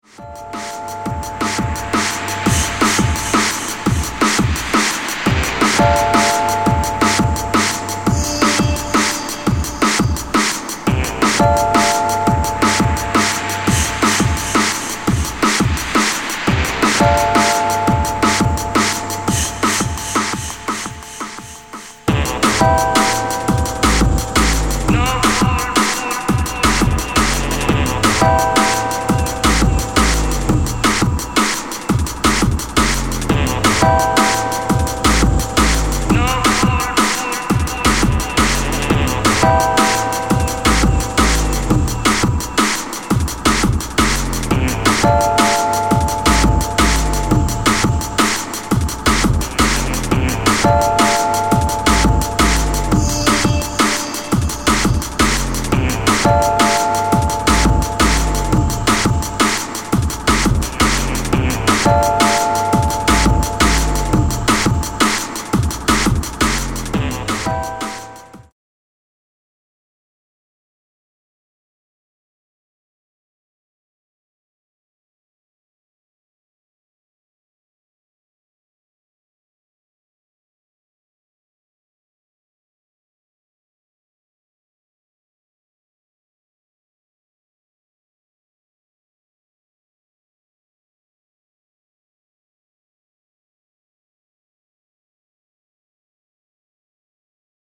Drum & Bass Mix